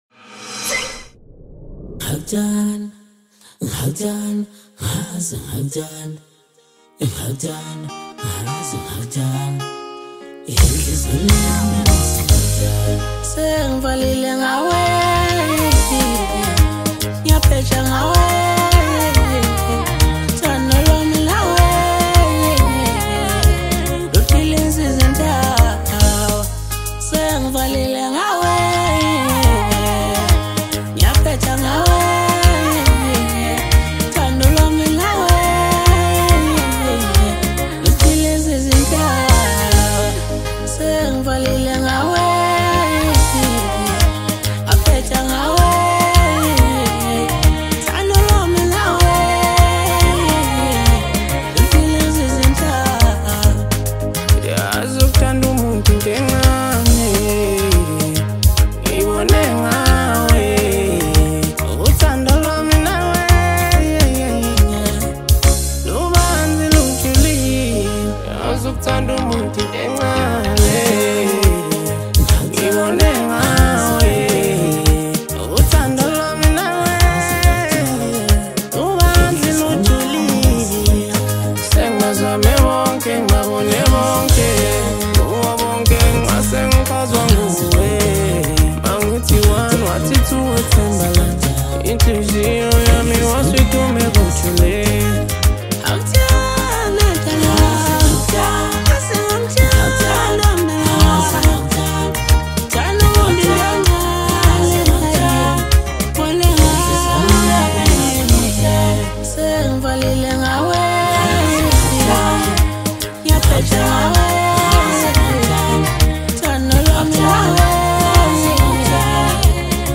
Maskandi
South African singer-songwriter